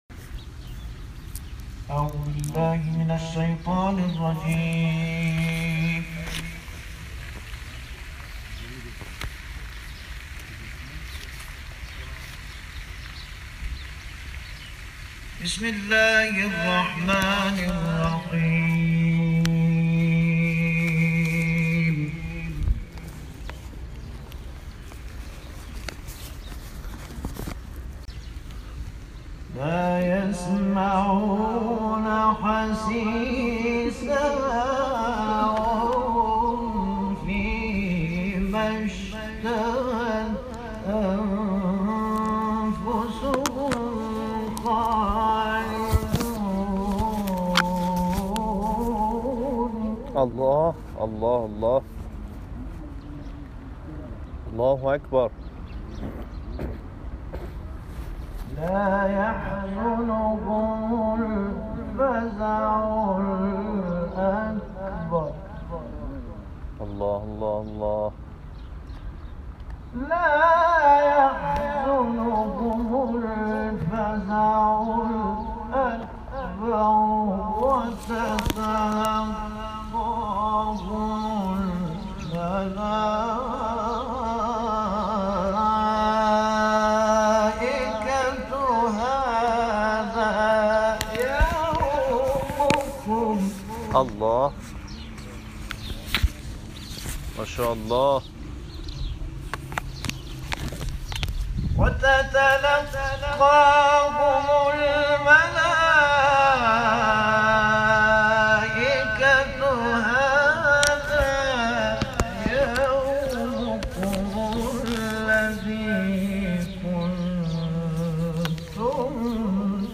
قاریان این کاروان در گلزار شهدای شهر اهواز، دارالقران اداره‌كل تبليغات اسلامى خوزستان و حسینیه امام رضا(ع) به تلاوت آیات نورانی کلام‌الله مجید پرداختند.
صوت/ قرائت قراء کاروان انقلاب در اهواز